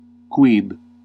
Ääntäminen
US : IPA : [ˈwʌt]